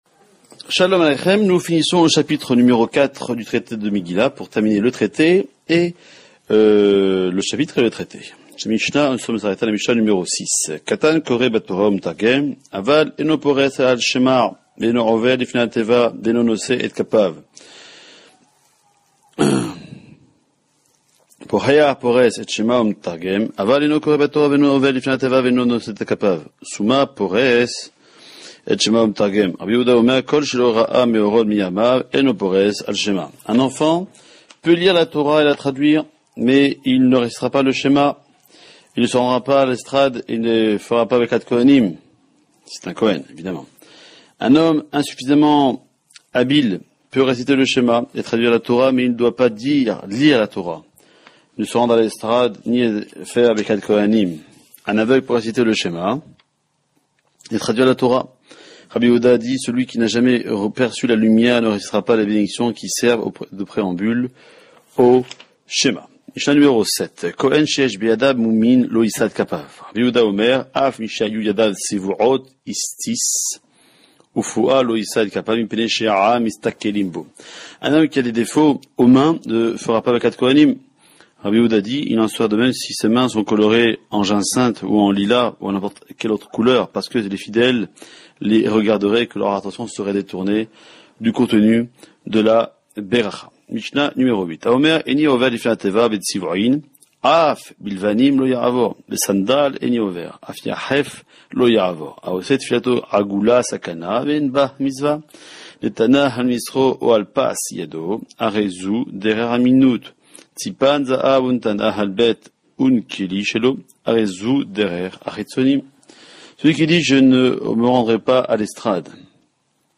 Cours d’environ 5 minutes et demi